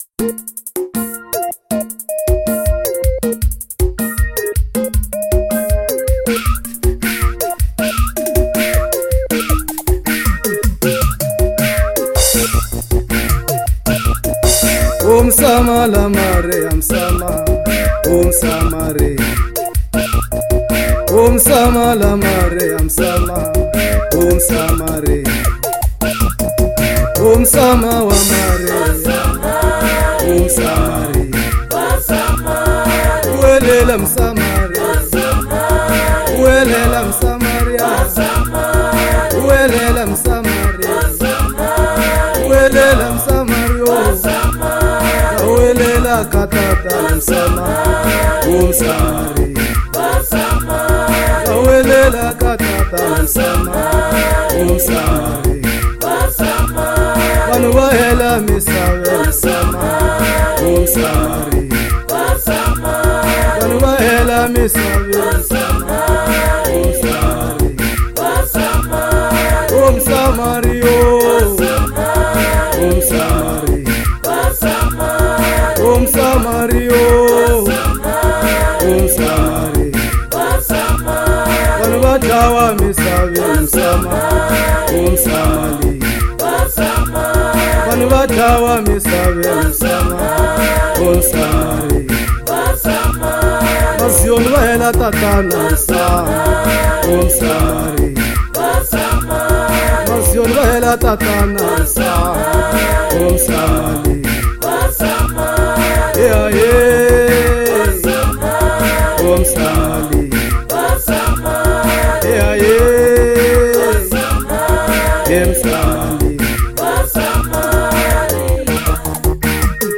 05:42 Genre : Gospel Size